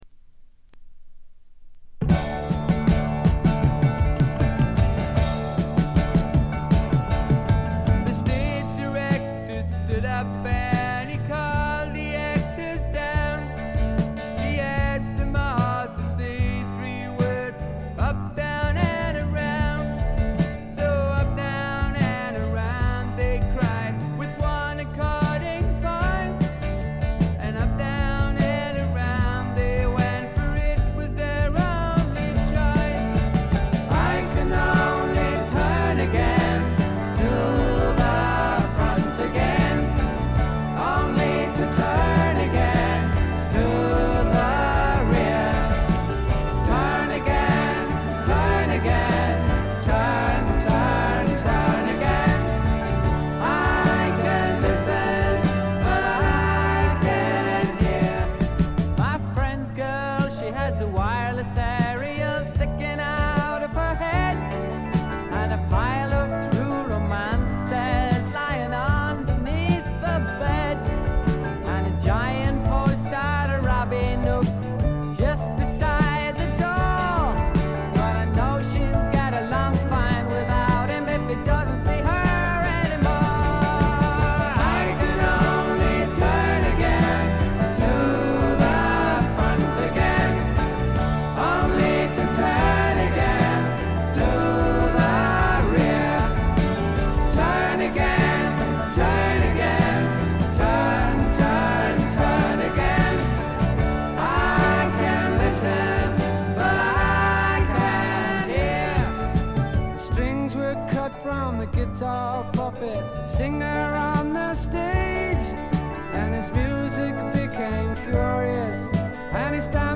わずかなノイズ感のみ。
英国フォークロック基本！
試聴曲は現品からの取り込み音源です。